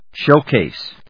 音節shów・càse 発音記号・読み方
/ˈʃoˌkes(米国英語), ˈʃəʊˌkeɪs(英国英語)/